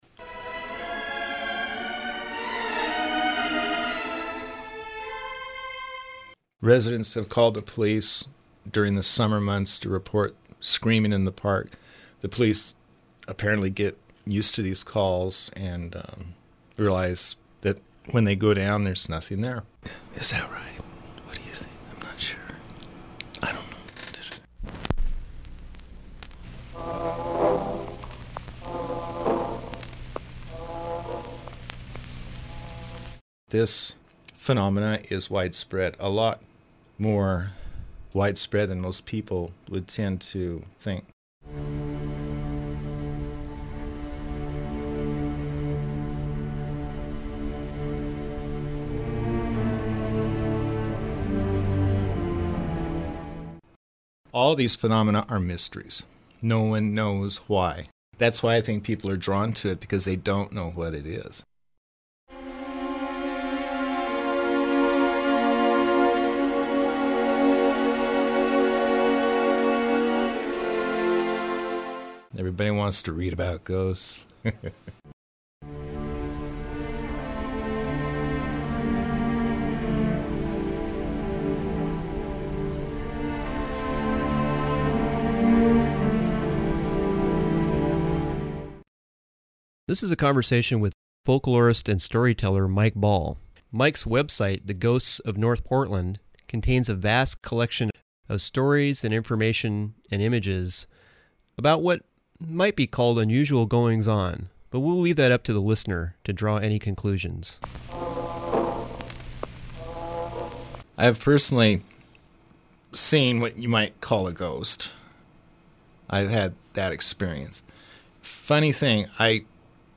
The Ghosts of North Portland: the static you hear in this episode is part of the show (not technical difficulties).